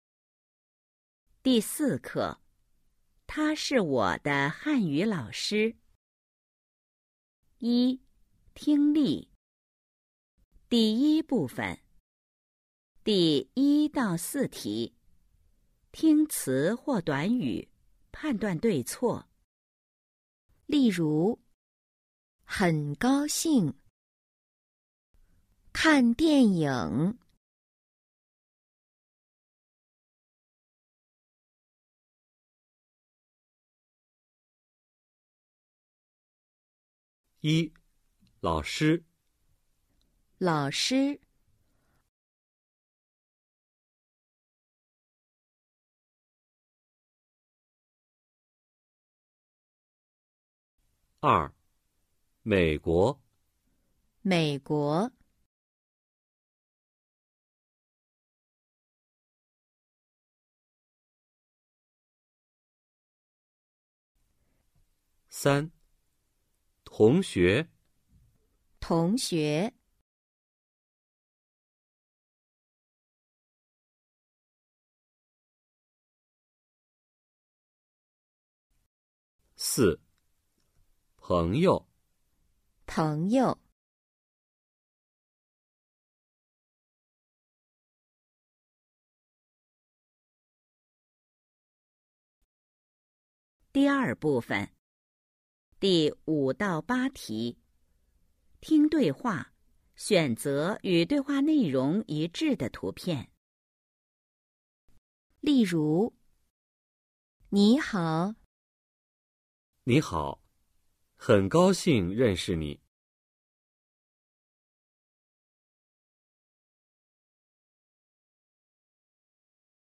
一、听力 Phần nghe 🎧 04-1
Câu hỏi 1-4: Nghe các từ/cụm từ và cho biết những hình sau có mô tả đúng thông tin bạn nghe được hay không.
Câu hỏi 9-12: Nghe câu mô tả tình huống và trả lời câu hỏi.